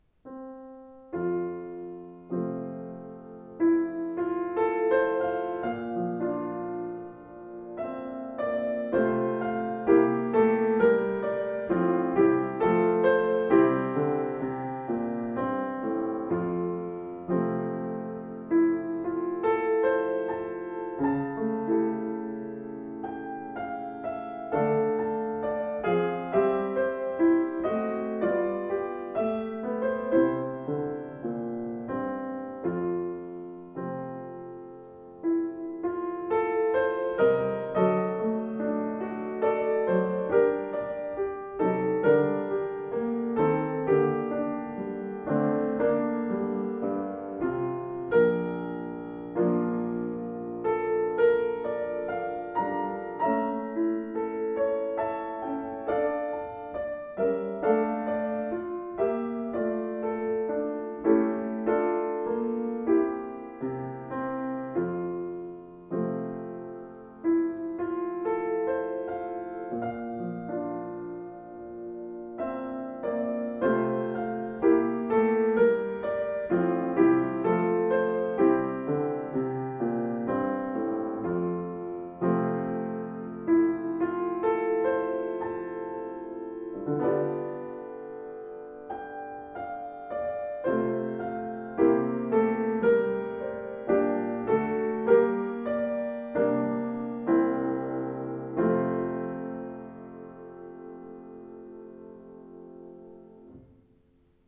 Schöner, ausdruckstarker Klang, angenehme Spielart